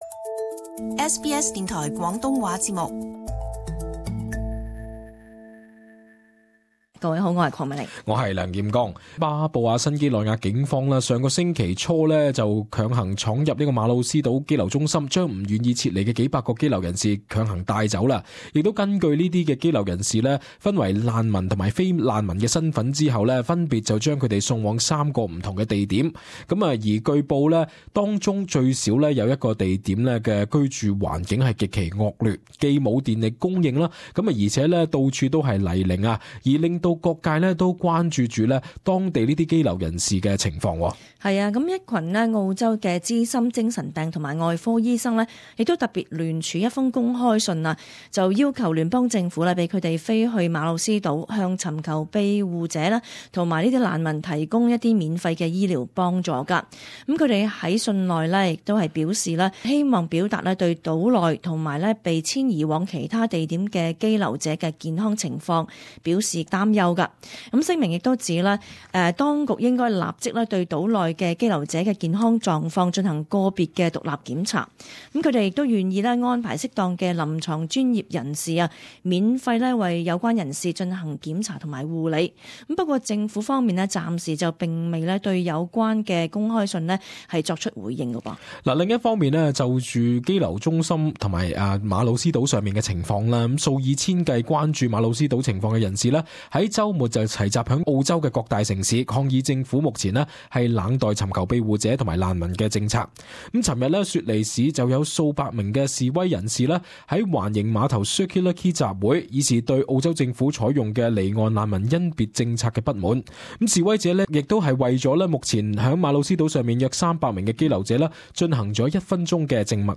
【時事報導】馬努斯島羈留者被迫遷引發全國各地示威